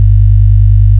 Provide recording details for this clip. Analog signal driving A/D